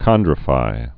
(kŏndrə-fī)